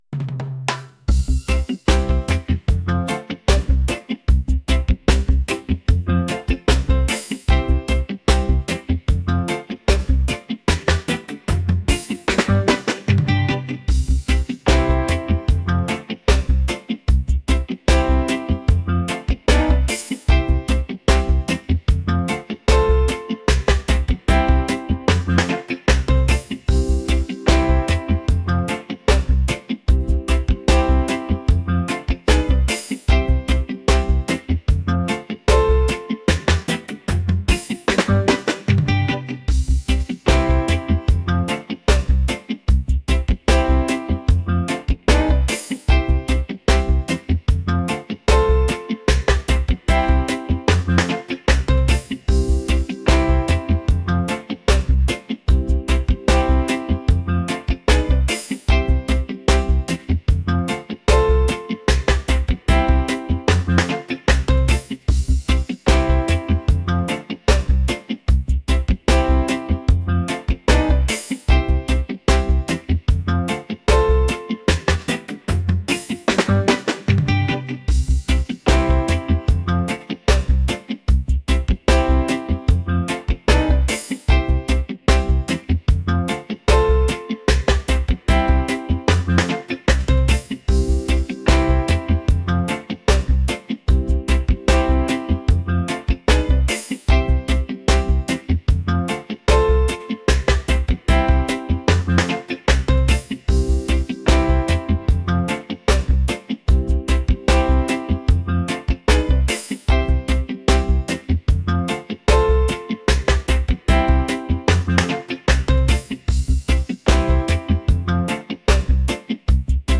reggae | upbeat | island | vibes